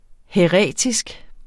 Udtale [ hεˈʁεˀtisg ]